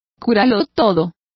Complete with pronunciation of the translation of panacea.